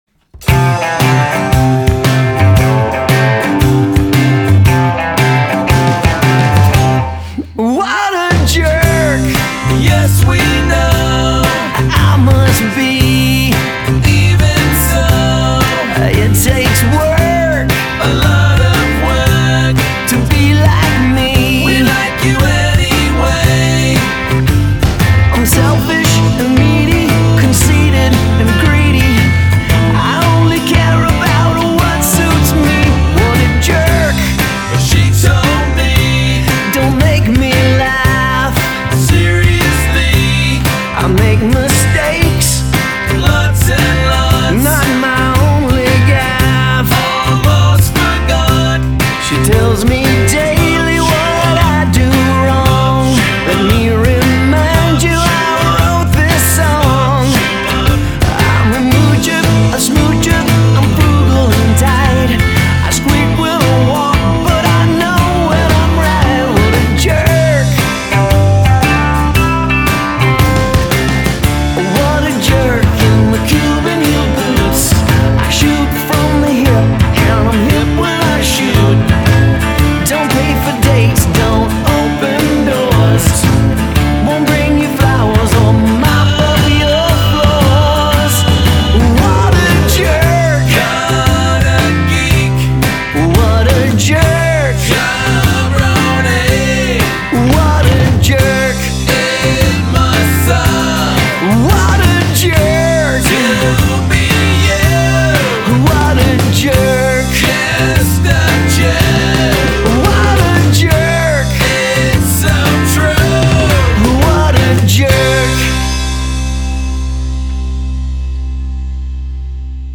hilarious, hooky